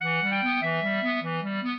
clarinet
minuet9-5.wav